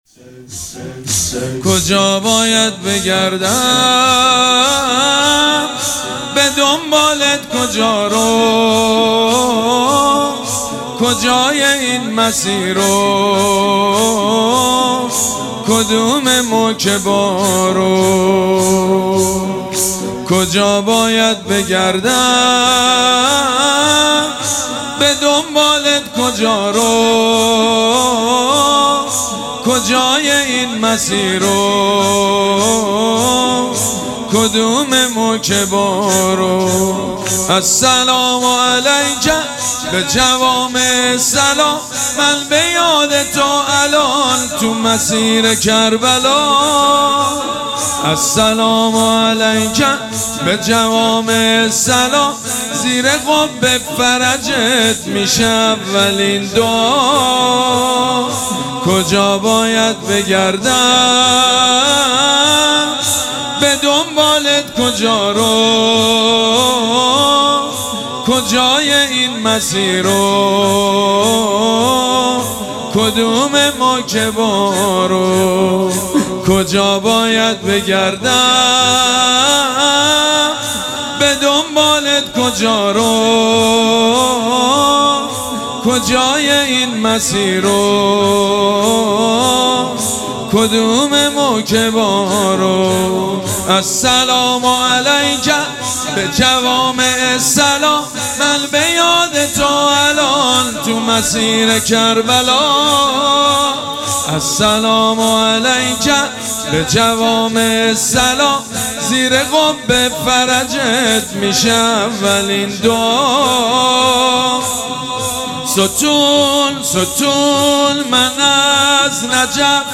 شب اول مراسم عزاداری اربعین حسینی ۱۴۴۷
مداح
حاج سید مجید بنی فاطمه